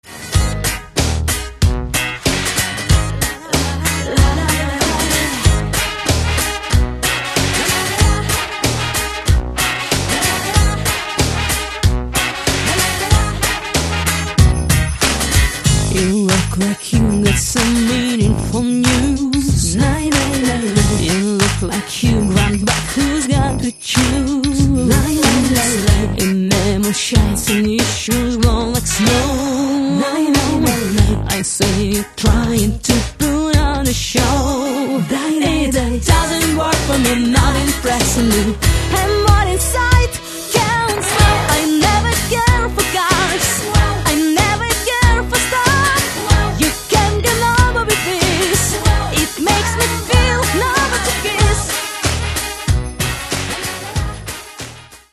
Каталог -> Поп (Легкая) -> Подвижная